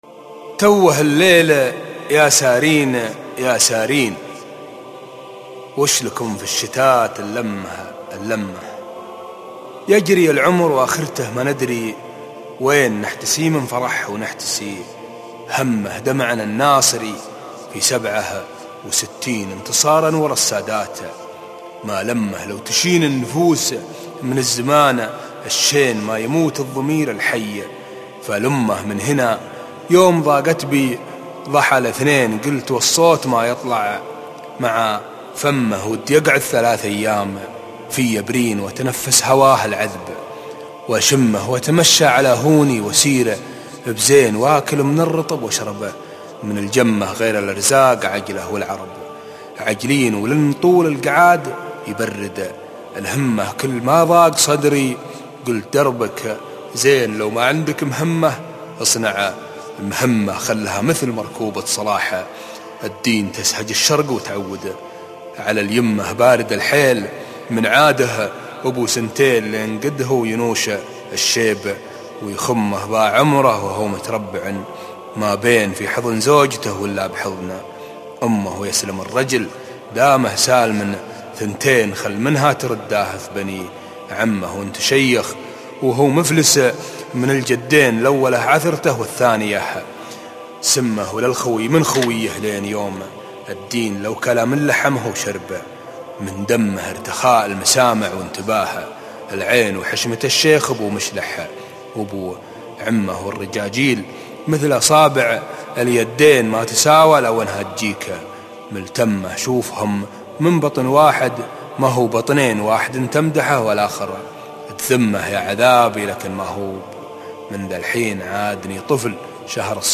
توّه الليل - القاء